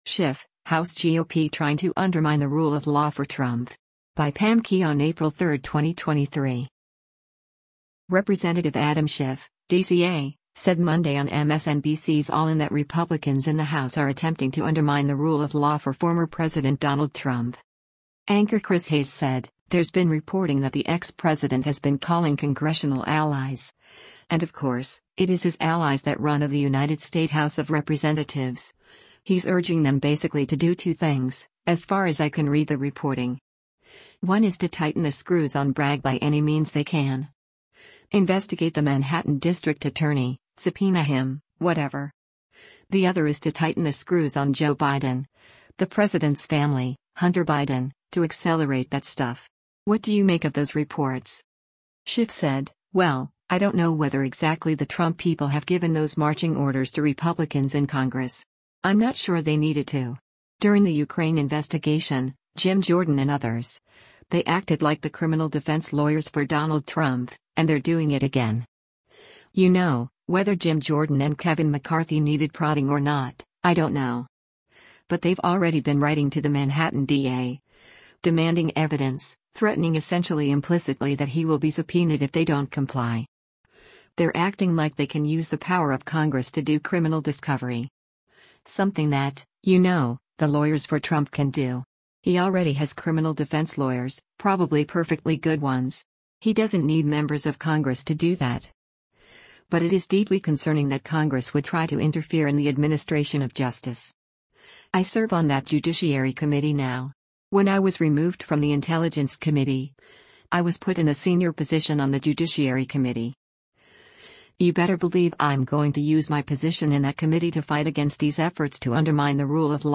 Representative Adam Schiff (D-CA) said Monday on MSNBC’s “All In” that Republicans in the House are attempting to “undermine the rule of law” for former President Donald Trump.